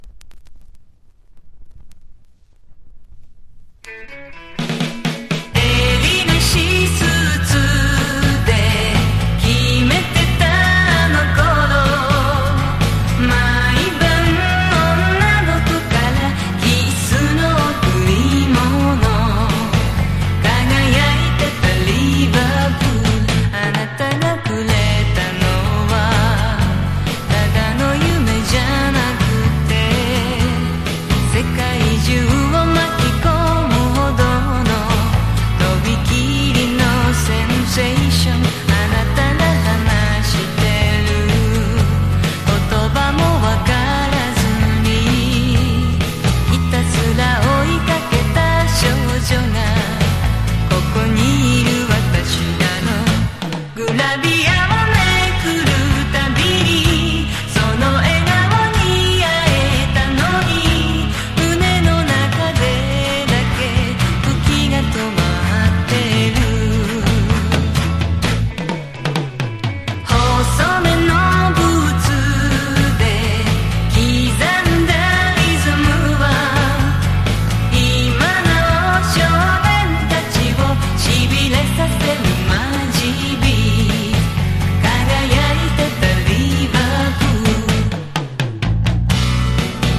サウンド面もビートルズを感じさせるビートルズ賛歌！
CITY POP / AOR